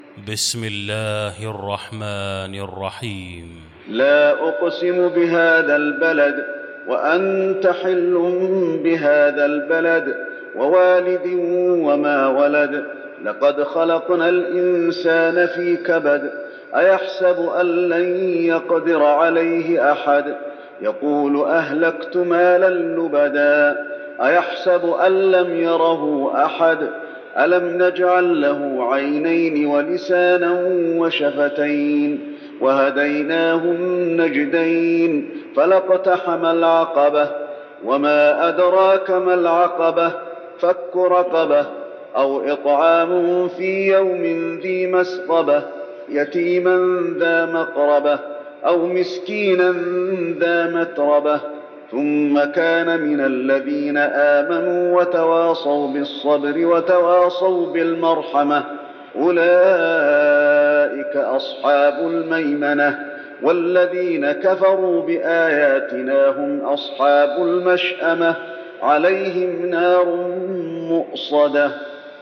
المكان: المسجد النبوي البلد